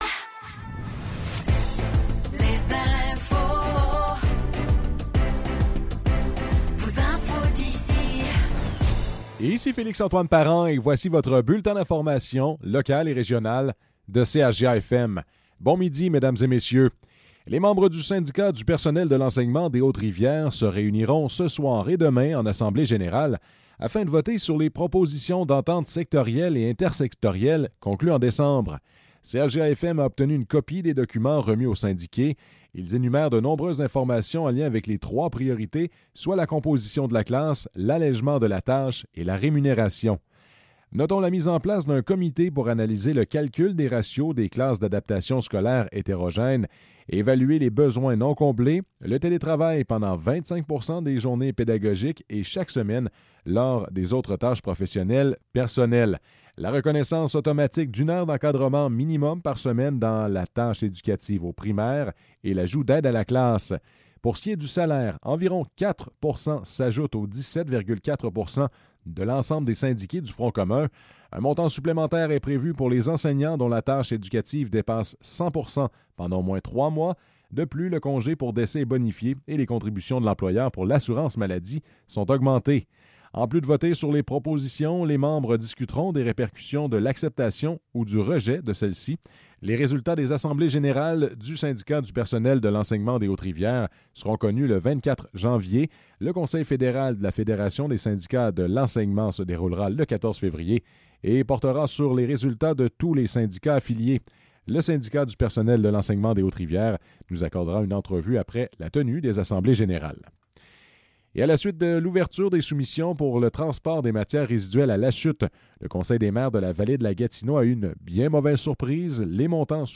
Nouvelles locales - 22 janvier 2024 - 12 h